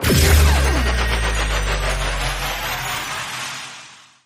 Appear_Scatter_Sound_Final.mp3